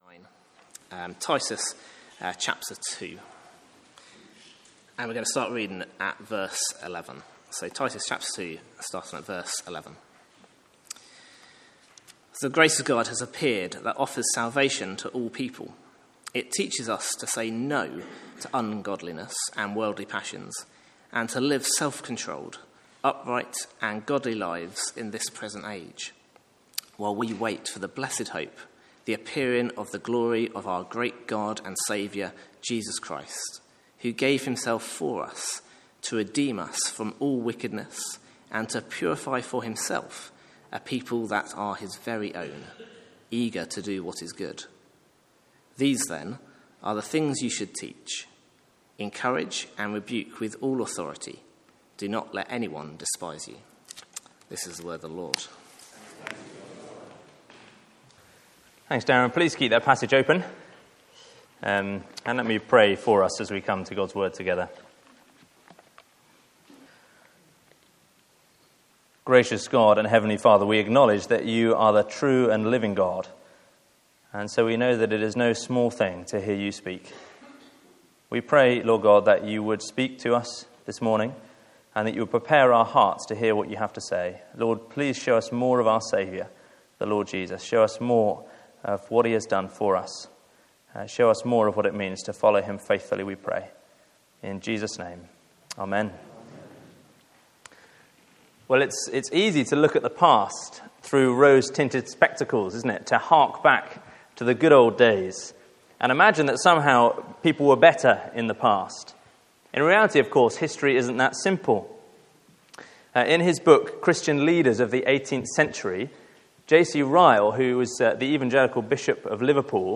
Sermons Archive - Page 76 of 188 - All Saints Preston